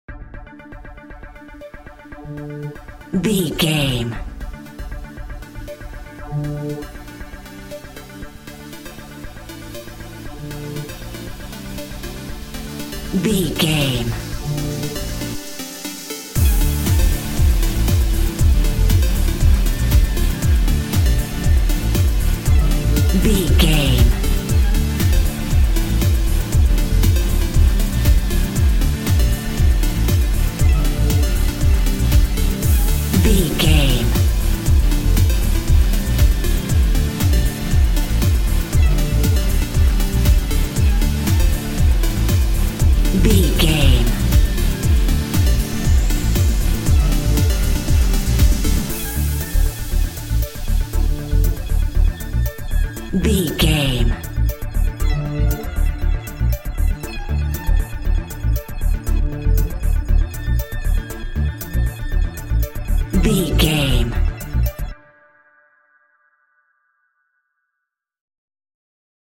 Pop Chart Electronic Dance Music 60 Sec.
Fast paced
In-crescendo
Aeolian/Minor
groovy
uplifting
energetic
bouncy
synthesiser
drum machine
house
electro dance
synth bass
upbeat